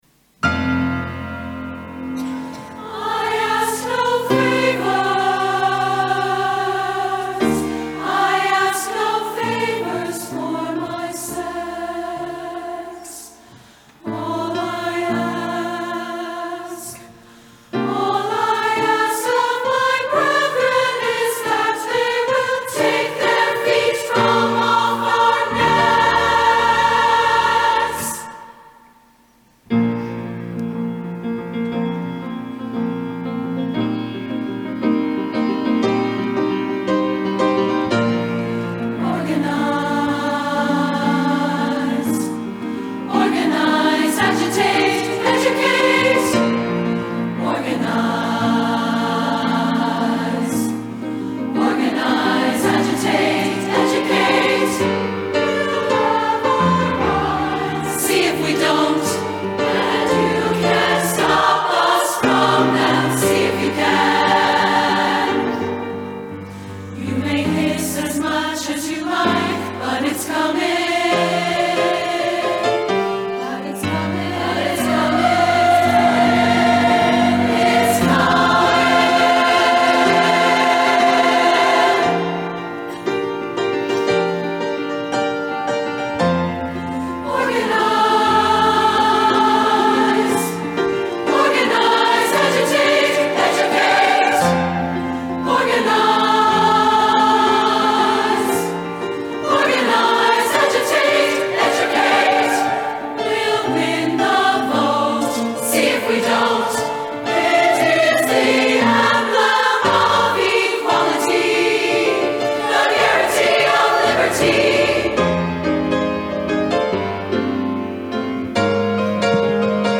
SSAA, piano